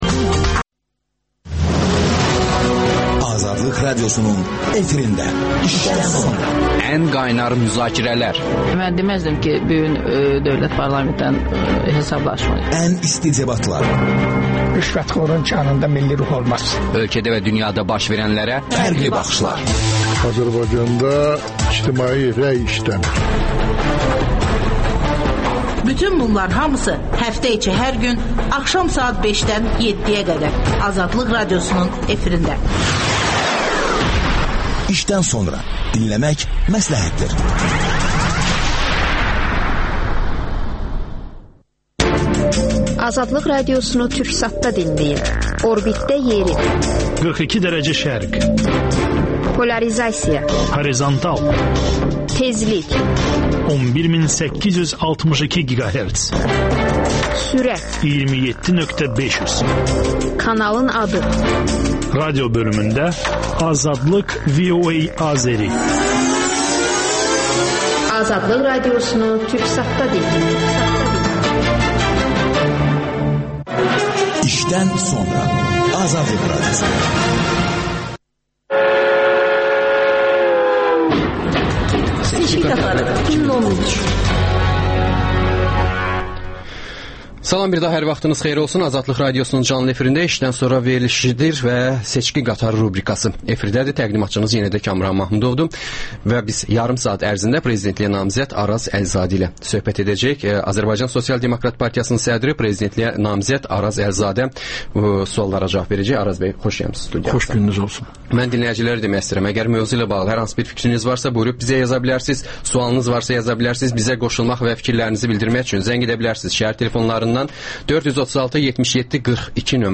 İşdən sonra - Prezidentliyə namizəd Araz Əlizadə ilə söhbət
ASDP-nin sədri, prezidentliyə namizəd Araz Əlizadə suallara cavab verir.